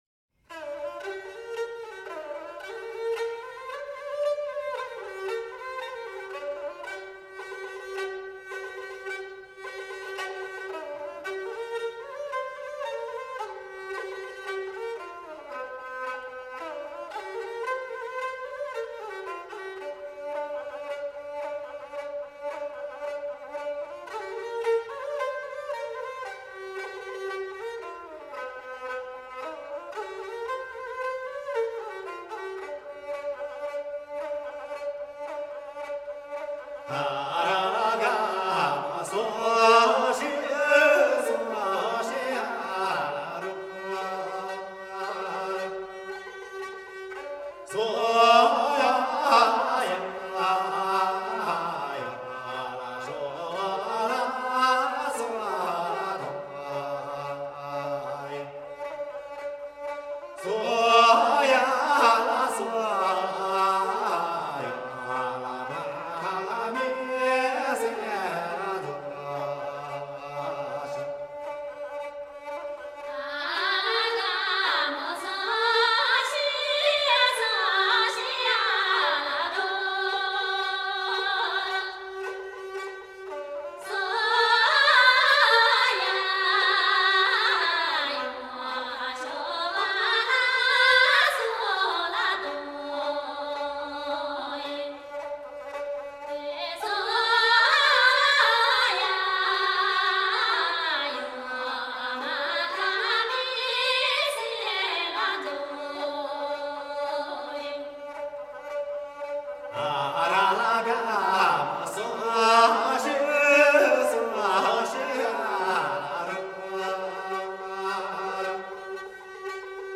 少数民族音乐系列
29首歌，旋律朴实悦耳，歌声高吭开怀，
充份展现厡野牧民和农村纯朴，直率奔放的民风。
都有嘹亮的歌声，一流的技巧。